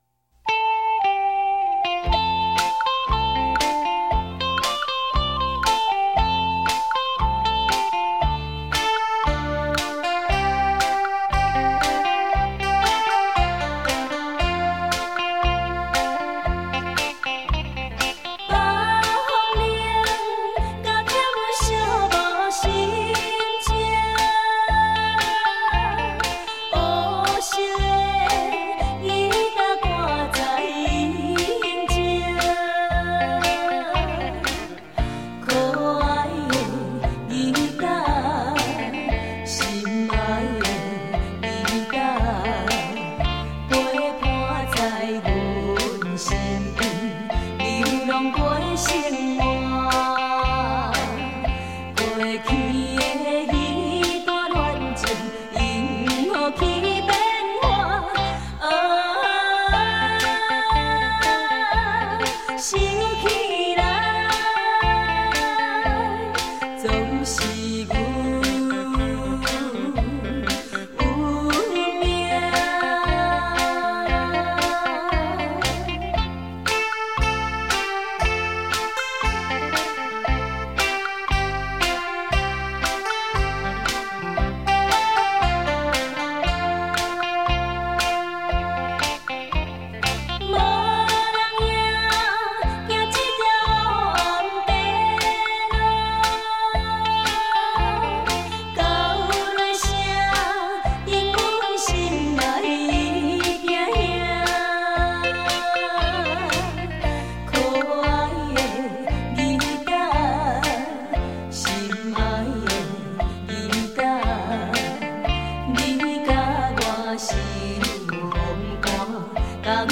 CD-雷射 高级版 日本原装录制